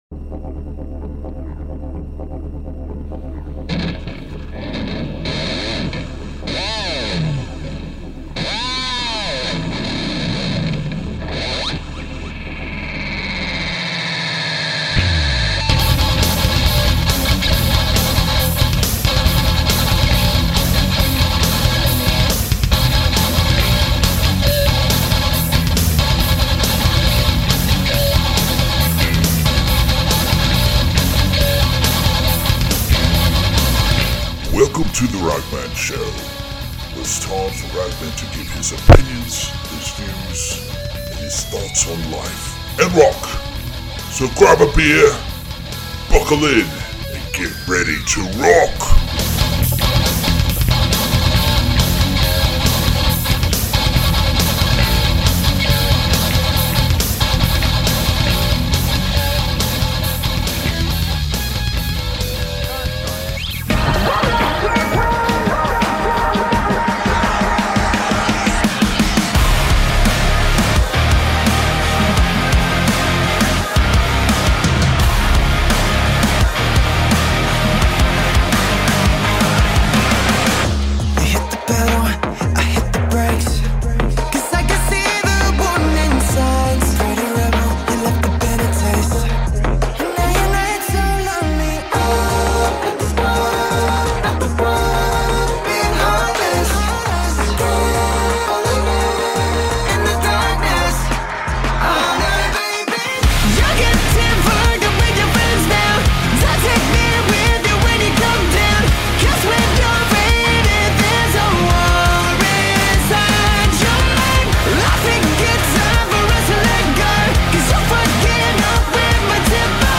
goes one on one with guitarist Joel Hoekstra (TSO, ex-Night Ranger, ex-Whitesnake) to talk about his later 13 projects album From the Fade out on Frontiers on 2/27/2